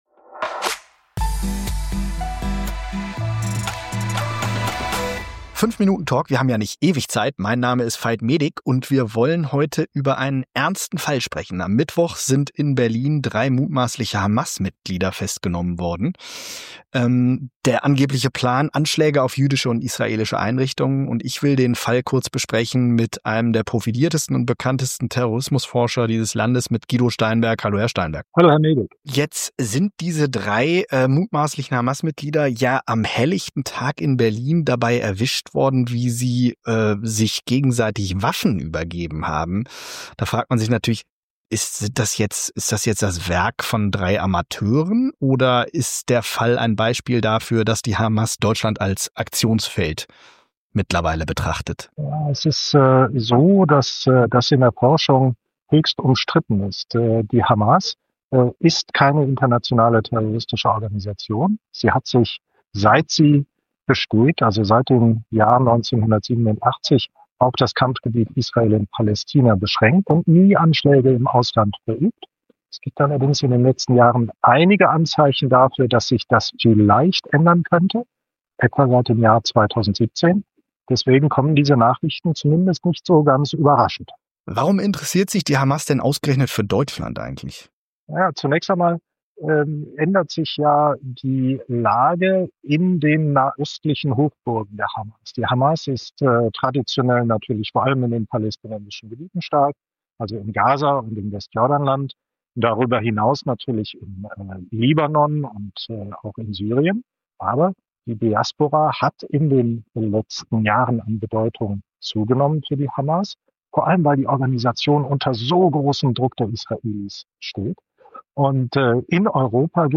spricht mit dem Terrorexperten